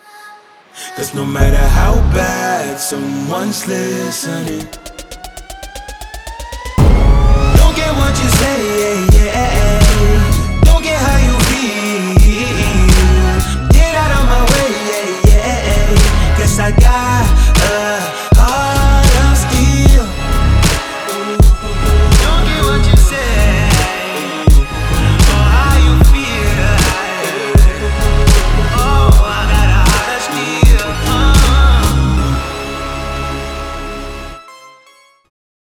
• Качество: 320, Stereo
мужской вокал
RnB